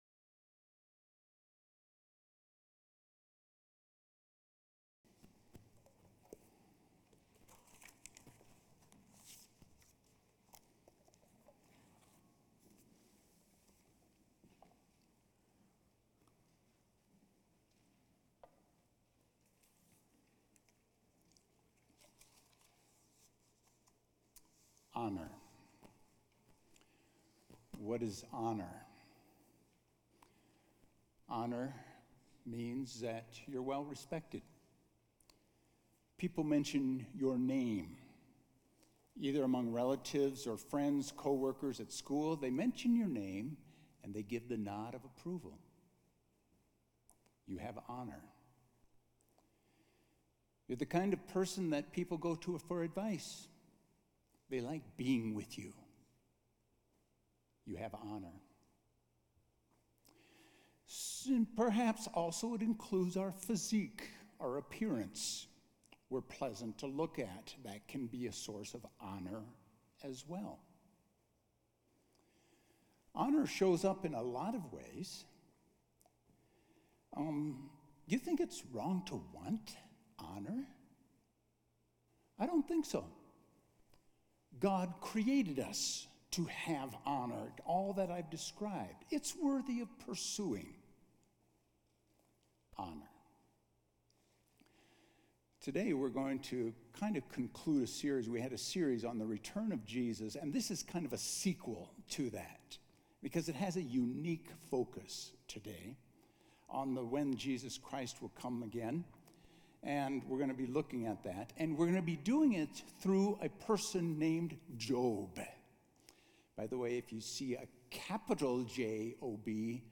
A message from the series "Jesus Return."